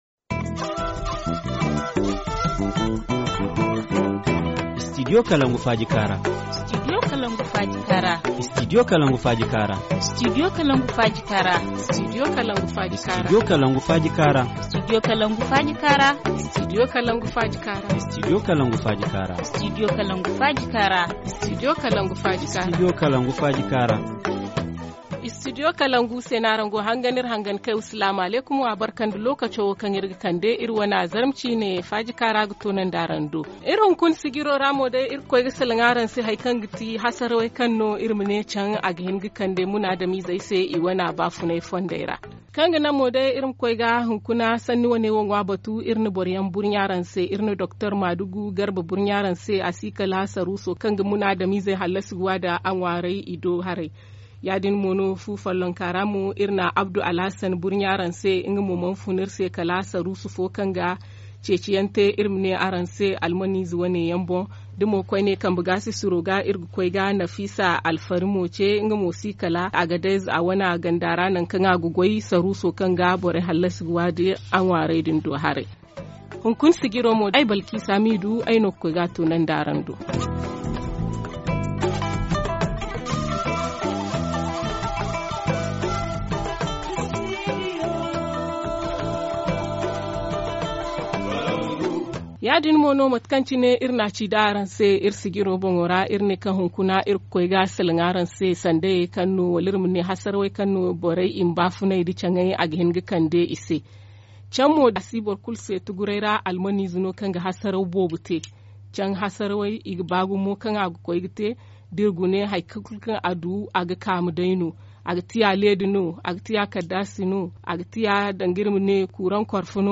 Forum Zarma du 07/01/2018 : Les dangers que représente la cohabitation entre les rats, les souris et les humains - Studio Kalangou - Au rythme du Niger